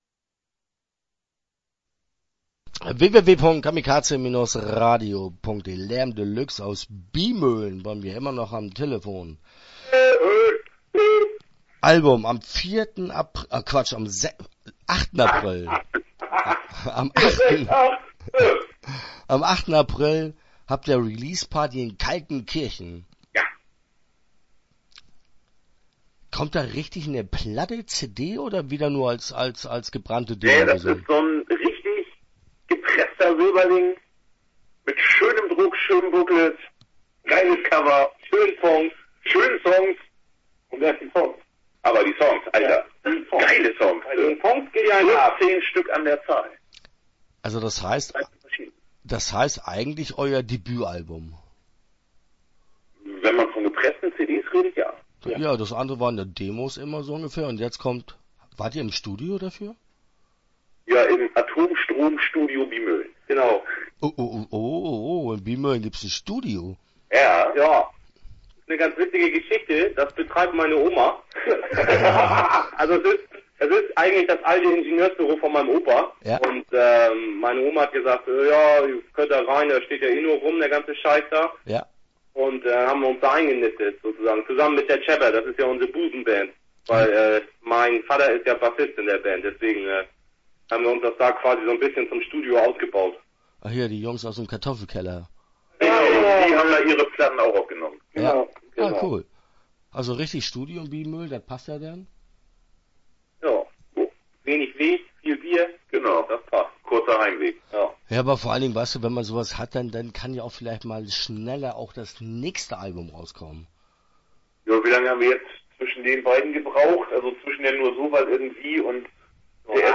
Start » Interviews » Lärm de Luxe